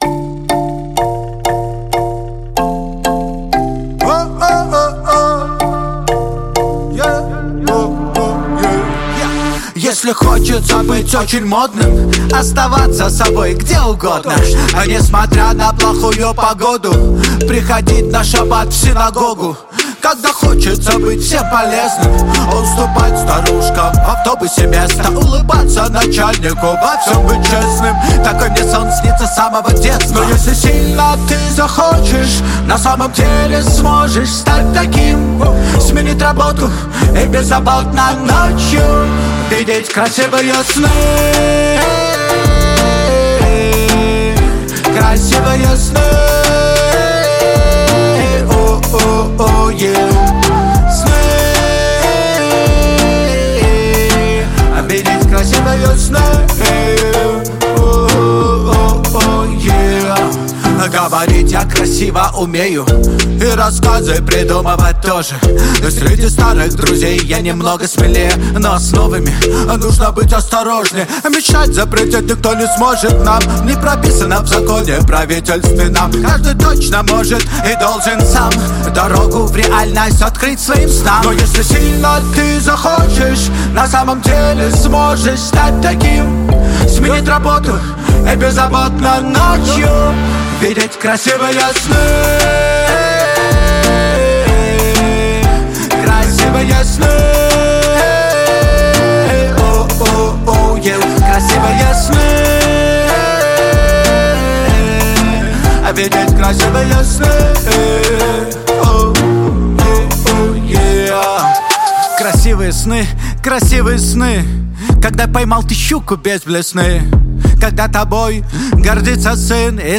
Открыл свой оригинальный стиль еврейского регги и хип-хоп.
Тенор